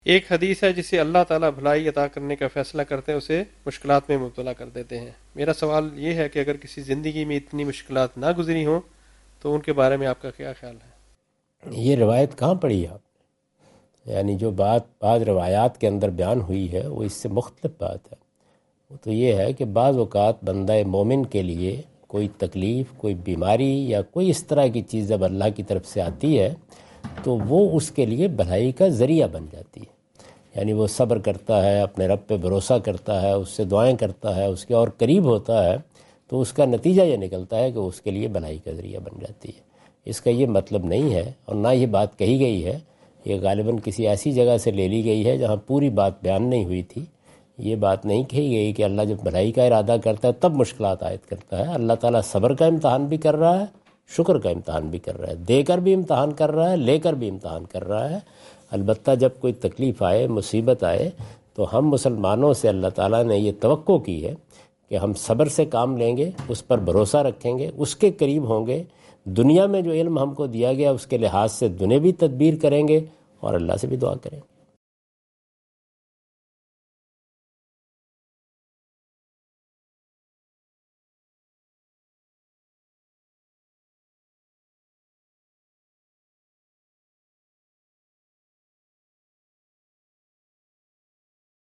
Category: English Subtitled / Questions_Answers /
Javed Ahmad Ghamidi answer the question about "Does Allah Test Us For Our Own Good?" during his Australia visit on 11th October 2015.
جاوید احمد غامدی اپنے دورہ آسٹریلیا کے دوران ایڈیلیڈ میں "کیا اللہ مشکلات بھلائی کے لیے نازل فرماتا ہے؟" سے متعلق ایک سوال کا جواب دے رہے ہیں۔